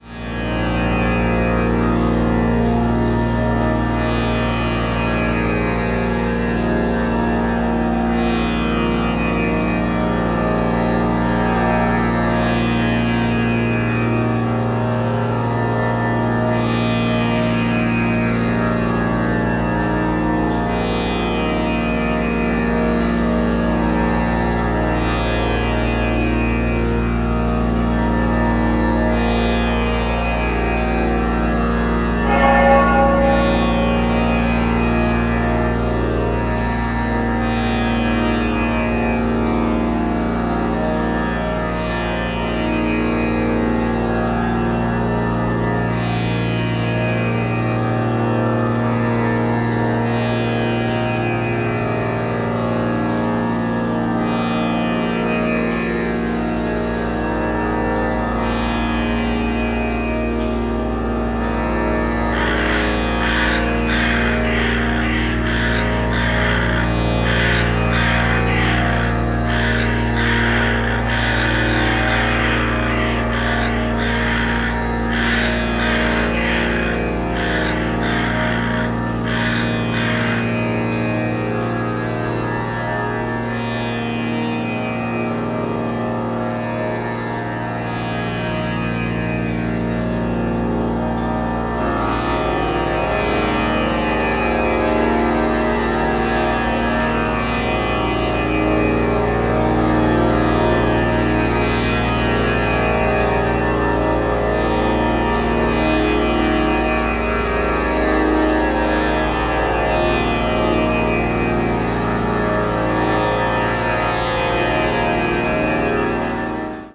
calmer fields of sound
Recorded in 2023